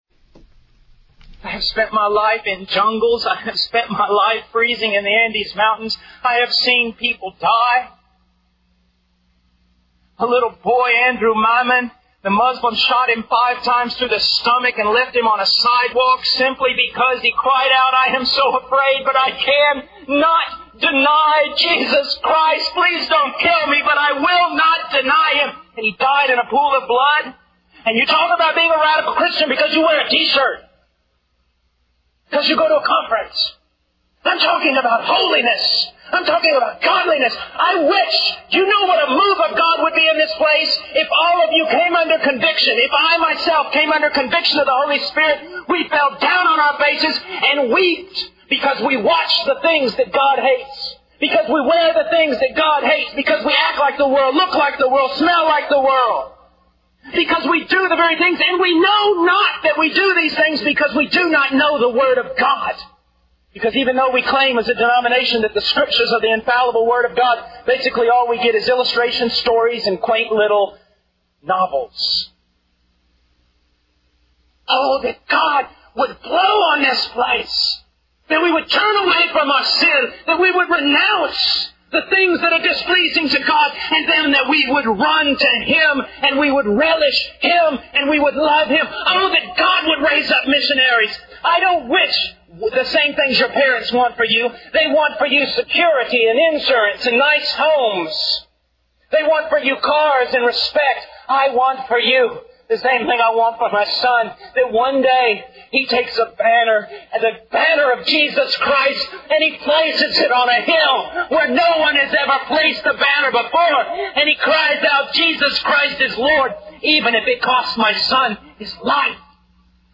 In this sermon, the speaker passionately shares his personal experiences of sacrifice and persecution for his faith in Jesus Christ.
Sermon Outline